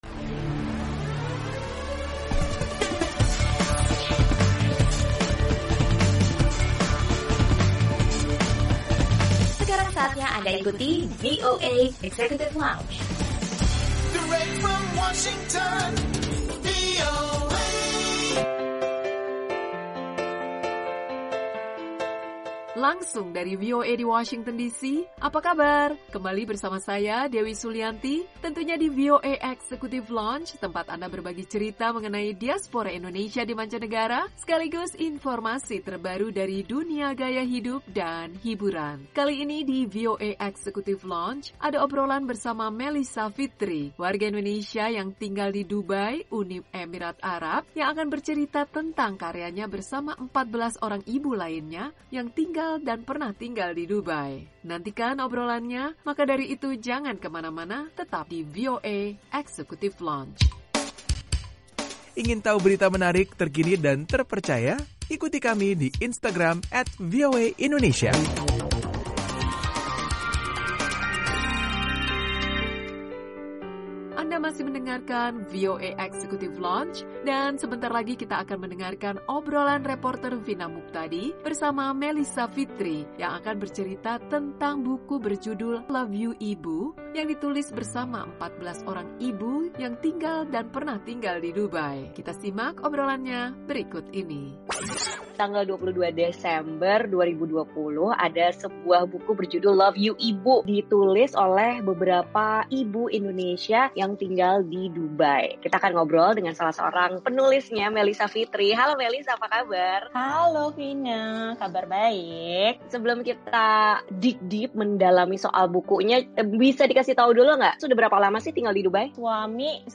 Obrolan reporter